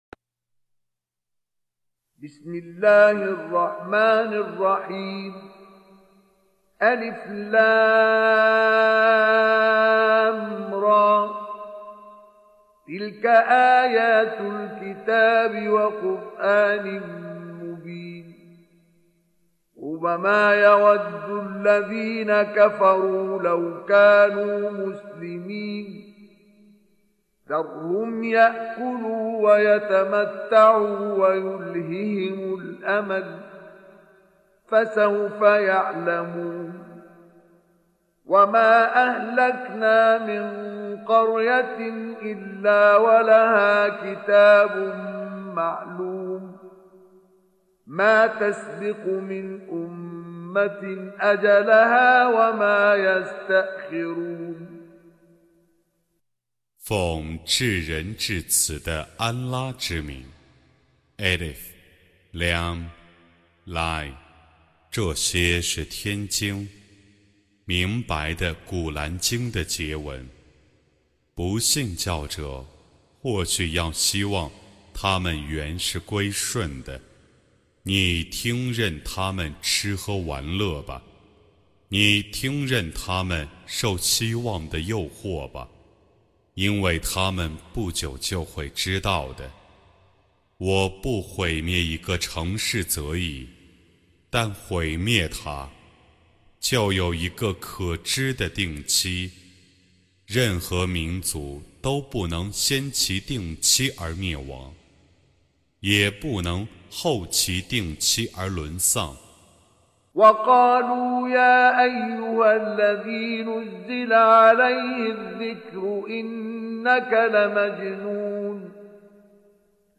Recitation
Surah Sequence تتابع السورة Download Surah حمّل السورة Reciting Mutarjamah Translation Audio for 15. Surah Al-Hijr سورة الحجر N.B *Surah Includes Al-Basmalah Reciters Sequents تتابع التلاوات Reciters Repeats تكرار التلاوات